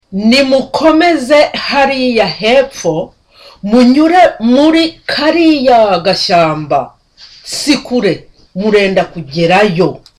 Dialogue: Buranga and Mukamisha on a trip to Kabagari
(Softly)